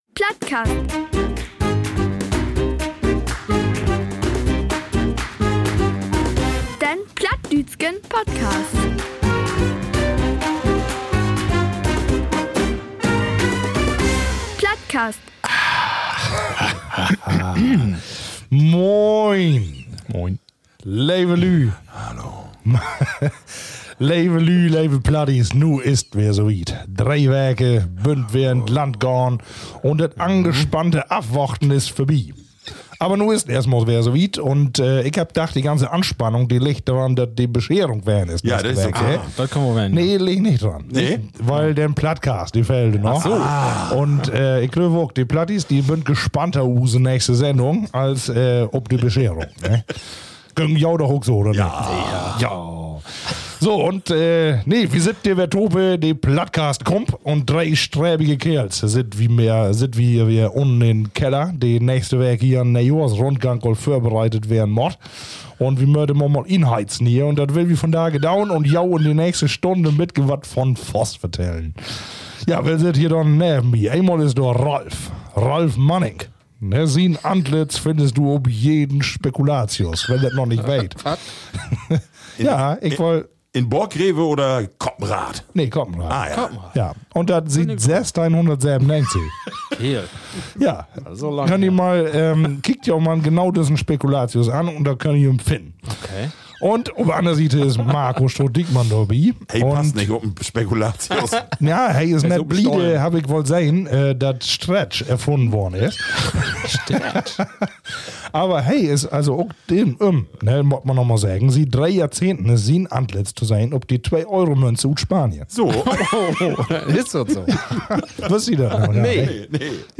Die drei Plattbarden wünschen sich zum Jahresende drei Dinge - Weltfrieden, Demokratie und Toleranz sowie Plattdeutsch als nationale Zweitsprache.